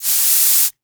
emptyspray.wav